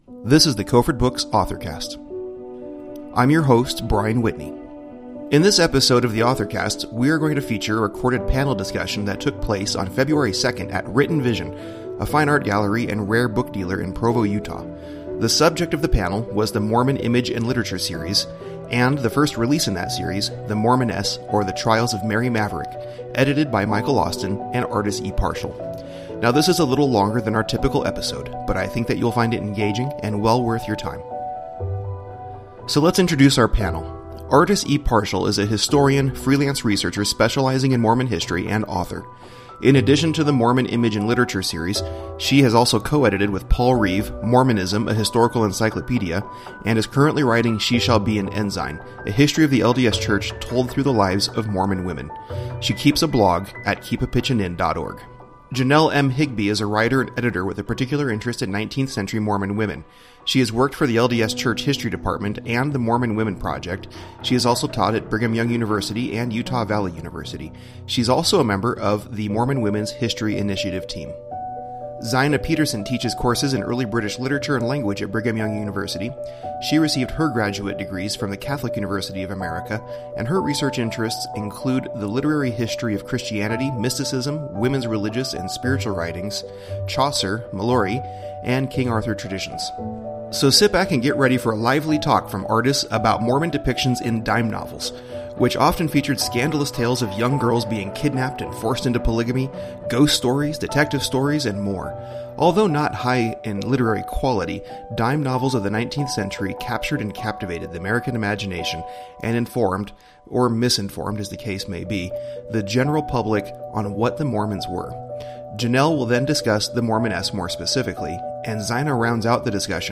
Our latest episode is the recording of a special panel event held at Writ & Vision in Provo, UT on February 2, 2016. The topic of the panel was The Mormon Image in Literature Series, and the first release in the series, The Mormoness; Or The Trials of Mary Maverick.
This was a fun and lively panel discussion that we hope you will enjoy!